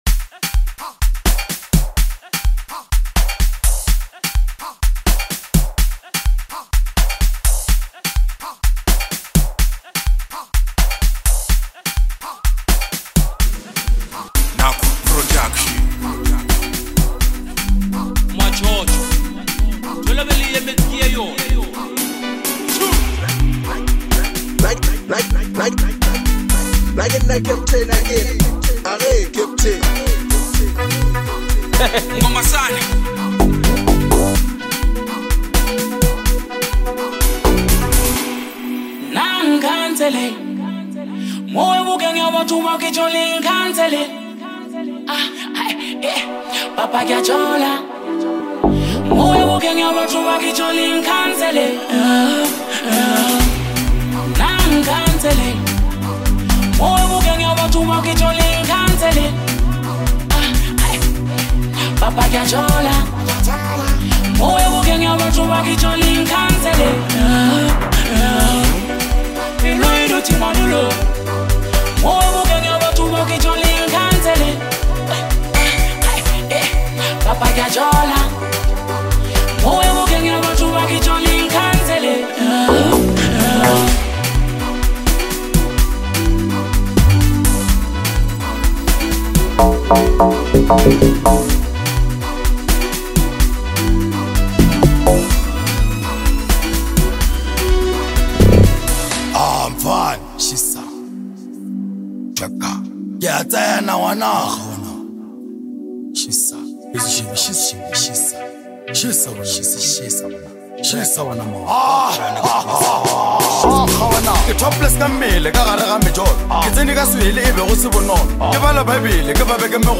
is a vibrant and energetic track